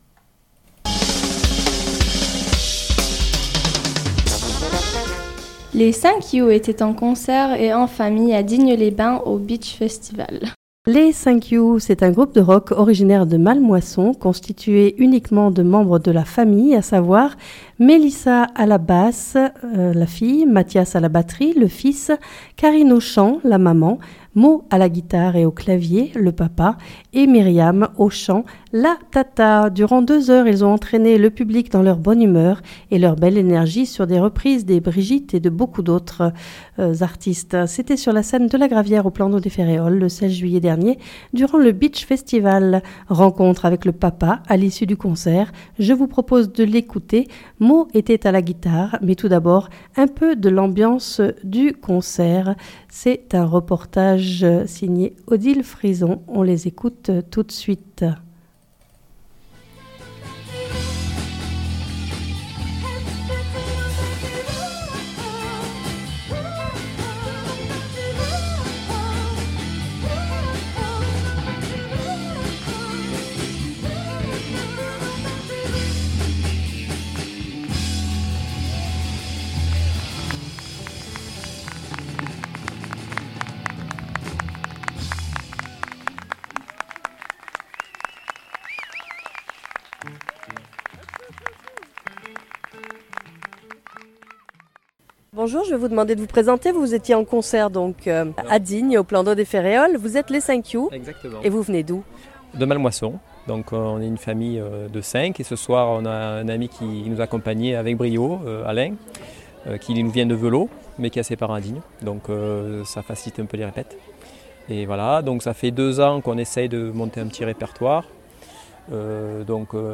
C’était sur la scène de la gravière au plan d’eau des Férréols le 16 juillet durant le Beach Festival.
Reportage